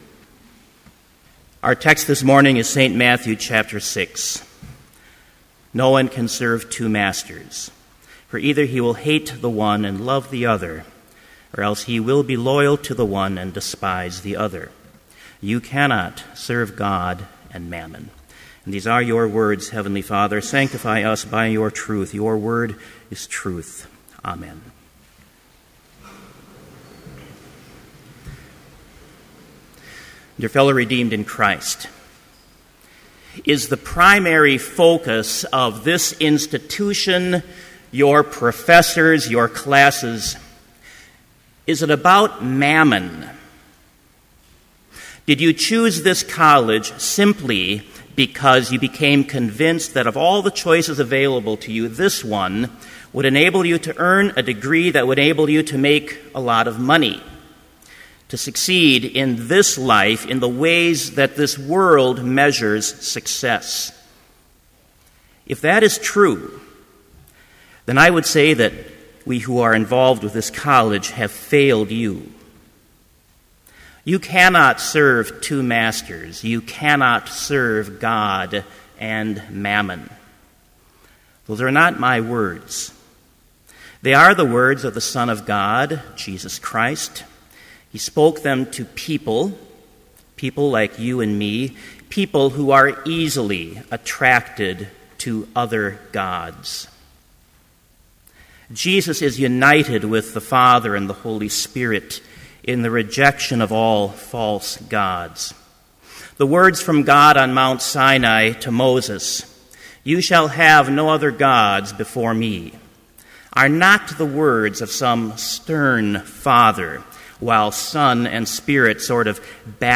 Complete Service
• Hymn 16, From All that Dwell
• Homily
This Chapel Service was held in Trinity Chapel at Bethany Lutheran College on Tuesday, September 18, 2012, at 10 a.m. Page and hymn numbers are from the Evangelical Lutheran Hymnary.